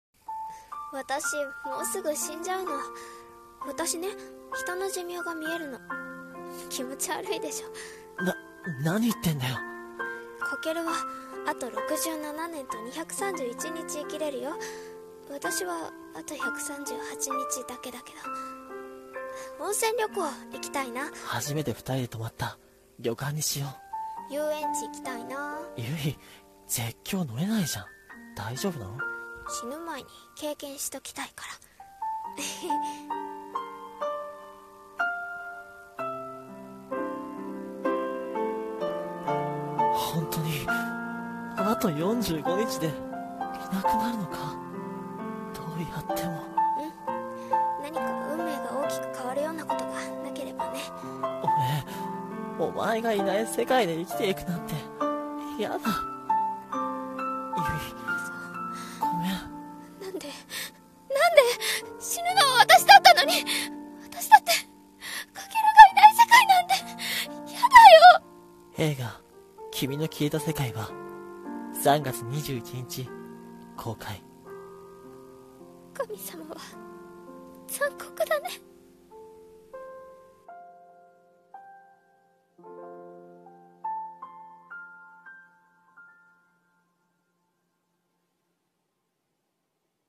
映画予告風声劇】君の消えた世界は。